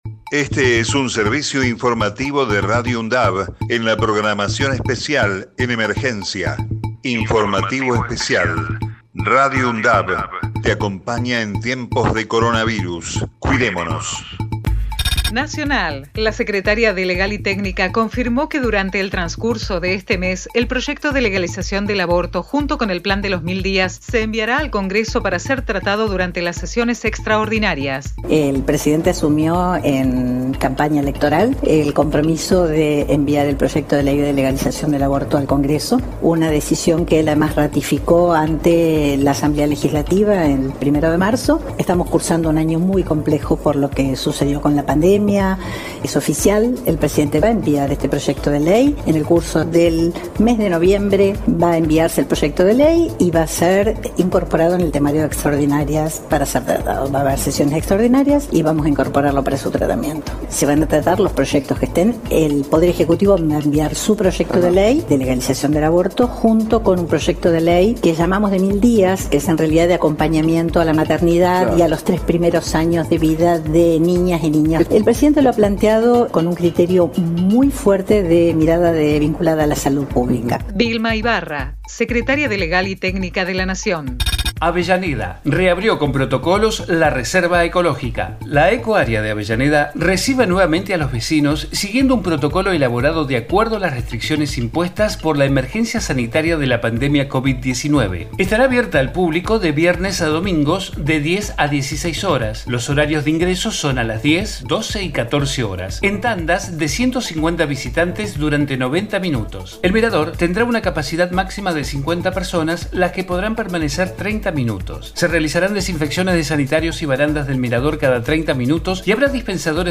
COVID-19 Informativo en emergencia 11 de noviembre 2020 Texto de la nota: Este es un servicio informativo de Radio UNDAV en la programación especial en emergencia.